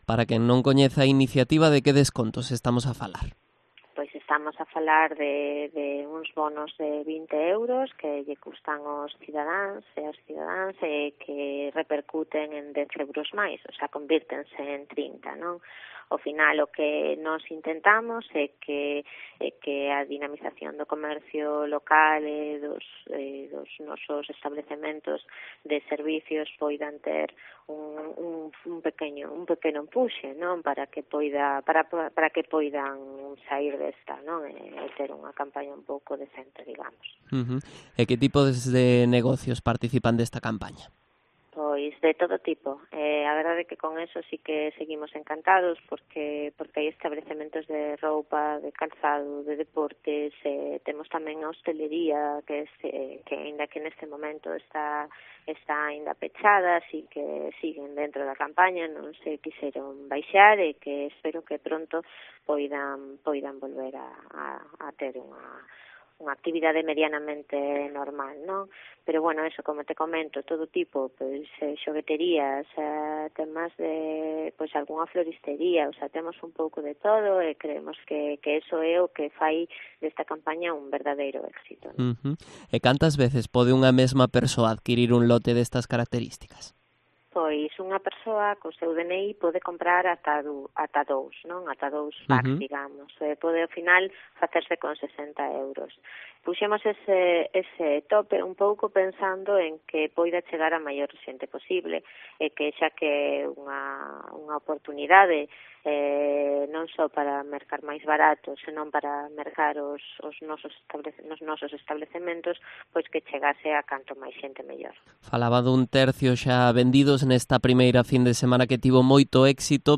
Entrevista a Alba Briones, concelleira de Promoción Económica en Vilagarcía